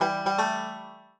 banjo_egga.ogg